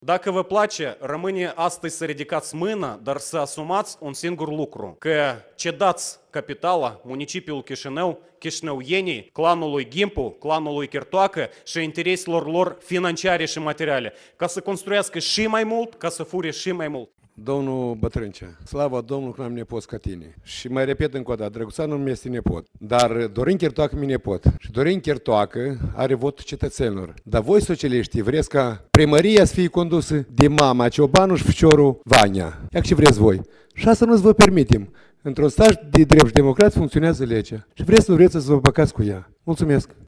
Reprezentantul socialiștilor Vlad Bătrâncea în dialog cu liderul Partidului Liberal Mihai Ghimpu.